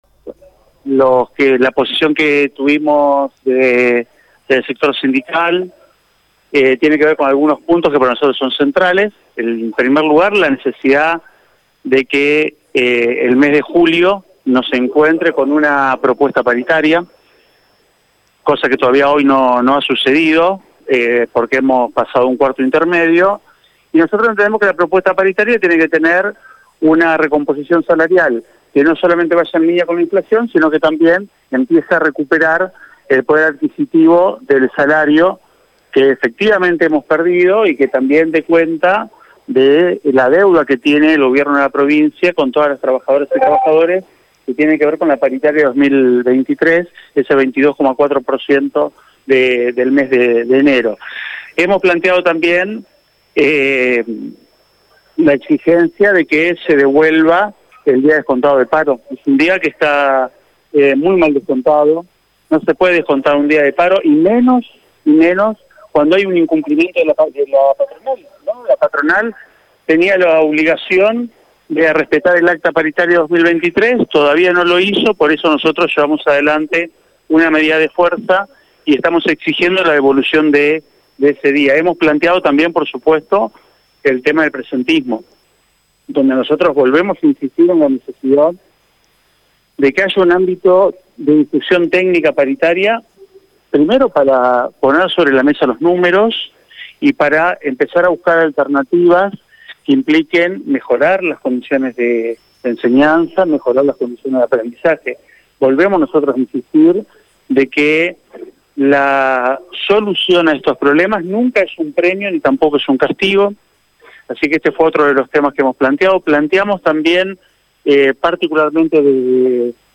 Escucha la palabra de los gremialistas en Radio EME: